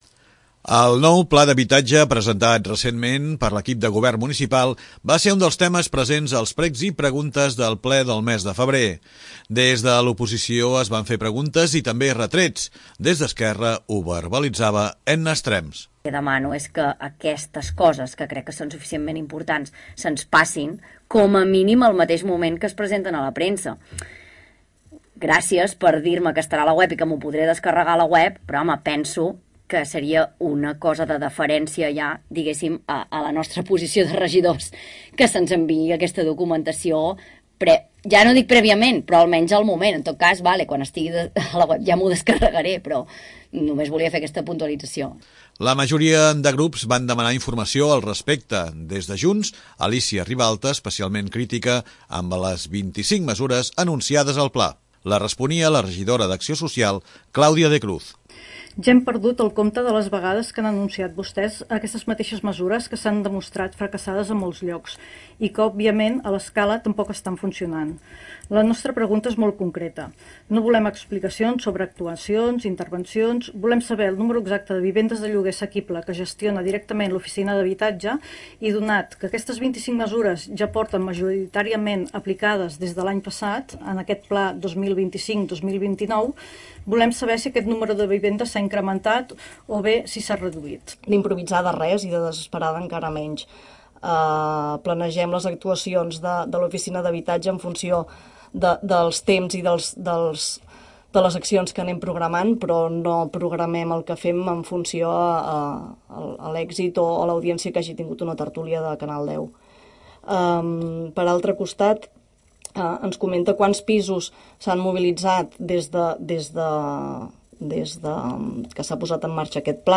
El nou Pla Local d'Habitatge presentat recentment per l'equip de govern municipal va ser un dels temes presents als precs i preguntes del ple municipal de febrer.